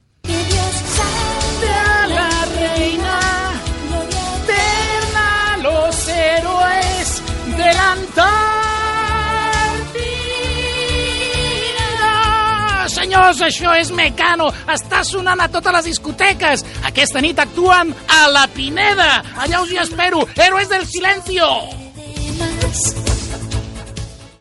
Presentació d'un tema musical
Recreació feta al programa "Islànda" de RAC 1 emès el 18 de febrer de l'any 2018.